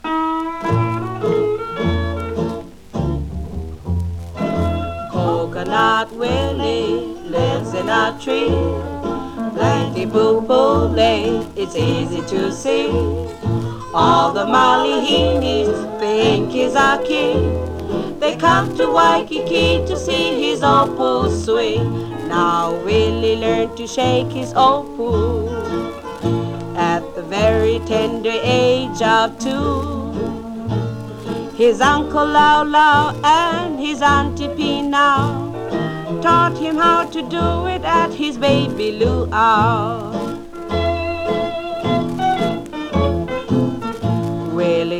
World, Hawaii　USA　12inchレコード　33rpm　Mono